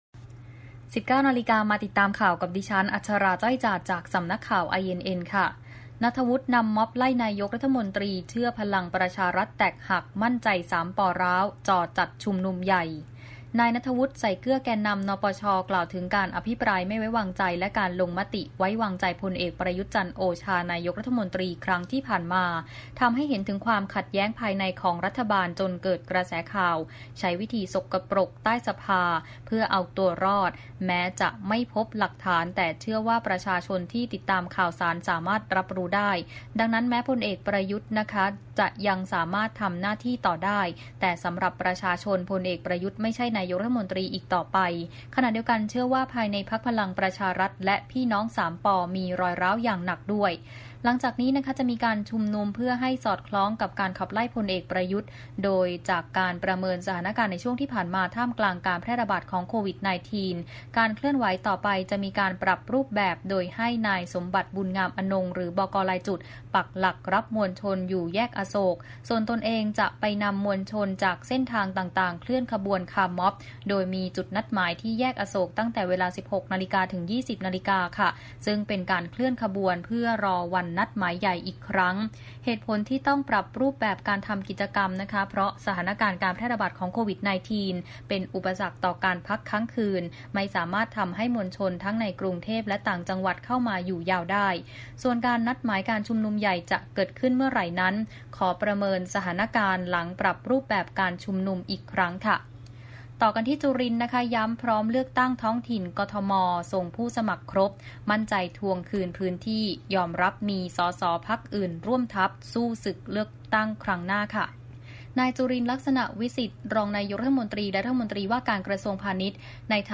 ข่าวต้นชั่วโมง 19.00 น.